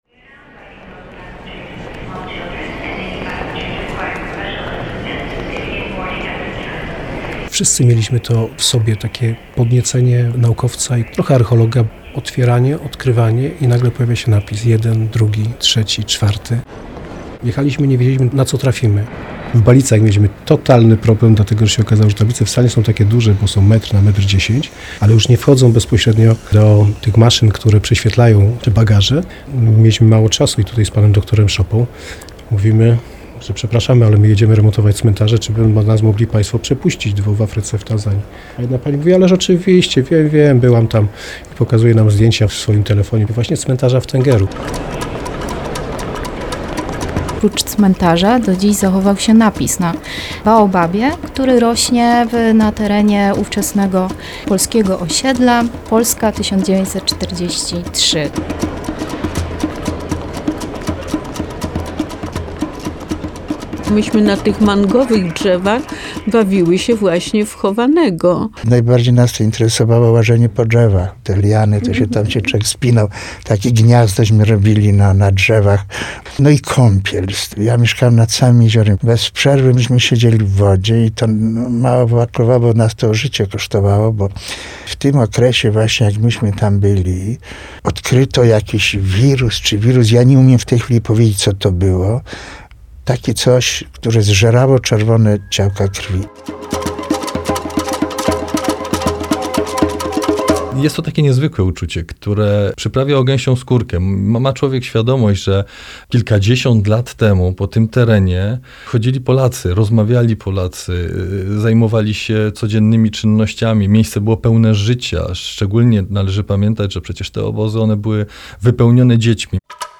Reportaż w Radiu Kraków